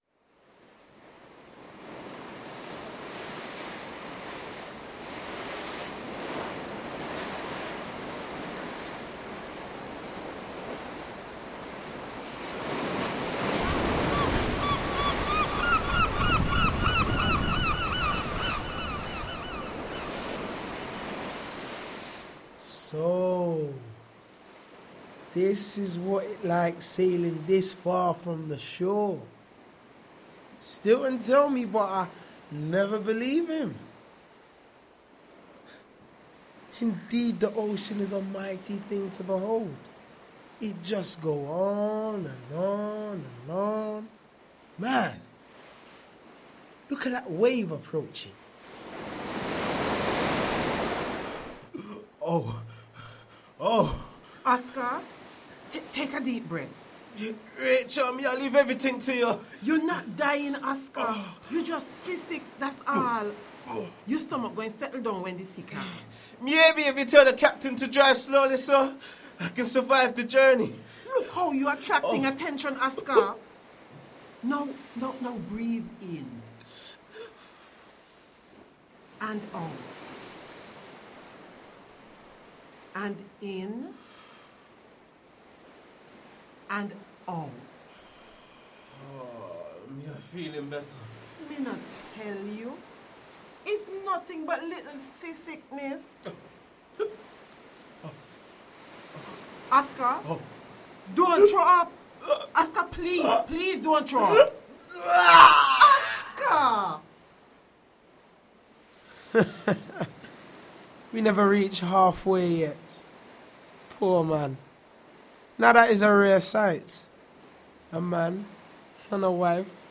The rehearsed reading for the first act of Oladipo Agboluaje’s Such a Long Journey is now available to listen to online via Research Support Hub.